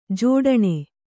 ♪ jōḍaṇe